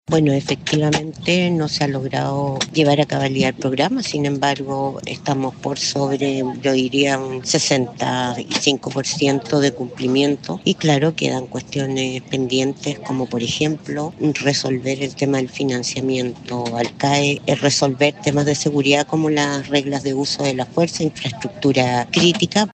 La diputada y subjefa de bancada del Frente Amplio, Lorena Fries, si bien reconoció que no se ha logrado avanzar en la totalidad del programa, refrendó lo logrado por la actual administración.